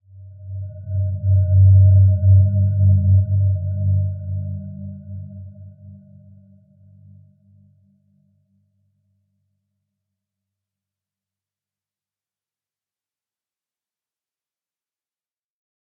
Dreamy-Fifths-G2-mf.wav